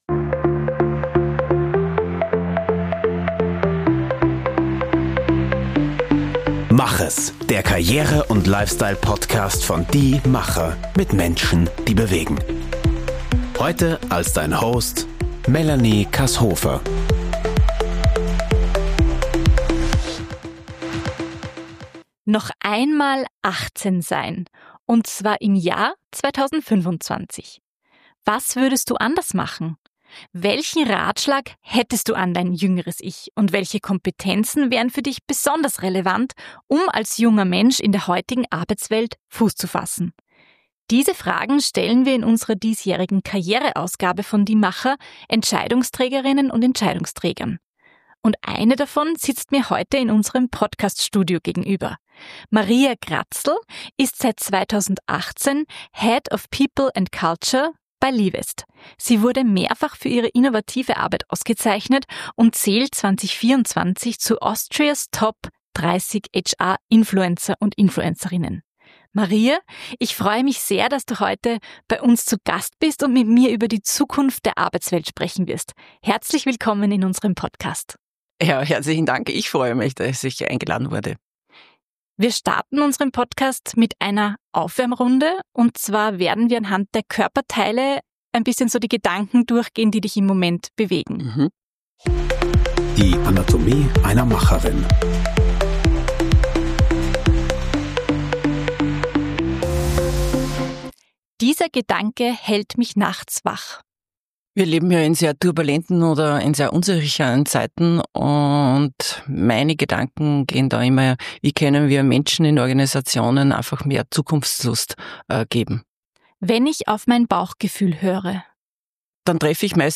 Ein Gespräch über die Herausforderungen und Chancen, die sich durch die rasante Entwicklung der Technologie und die sich wandelnden Anforderungen an die Arbeitswelt ergeben.